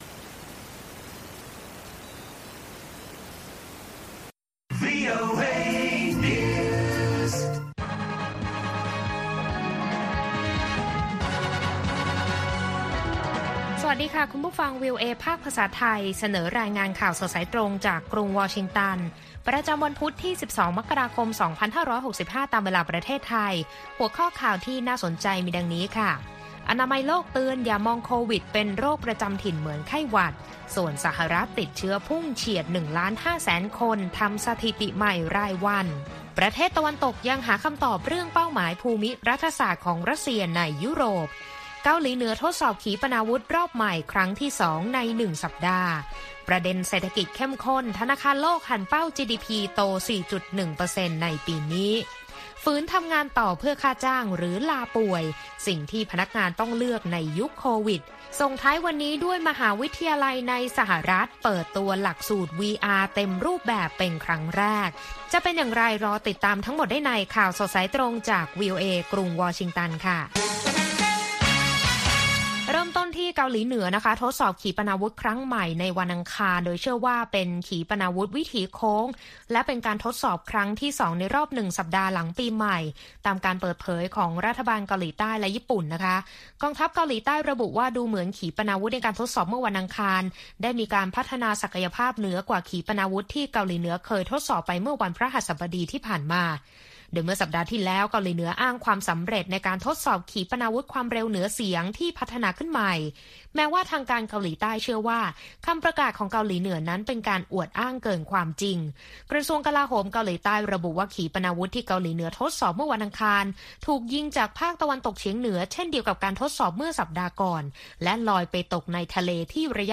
ข่าวสดสายตรงจากวีโอเอ ภาคภาษาไทย ประจำวันพุธที่ 12 มกราคม 2565 ตามเวลาประเทศไทย